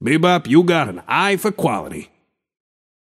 Shopkeeper voice line - Bebop, you got an eye for quality.
Shopkeeper_hotdog_t4_bebop_03.mp3